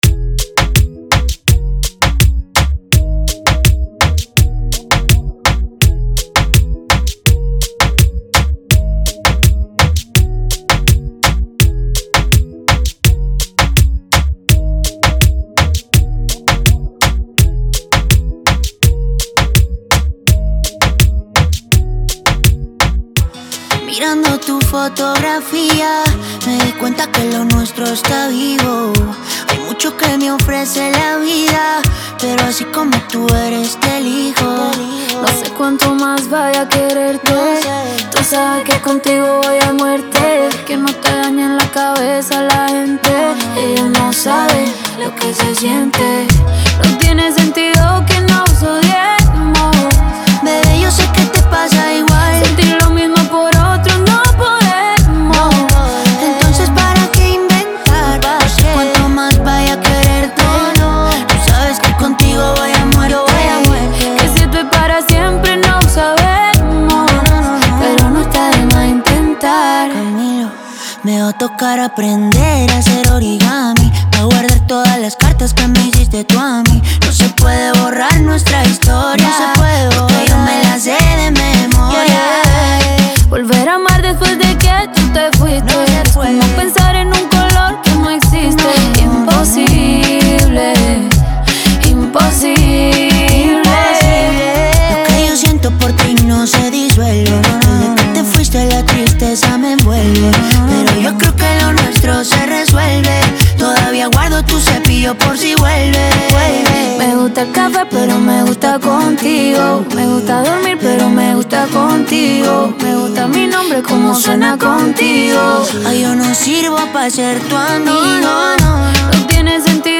Genre: Reggaeton.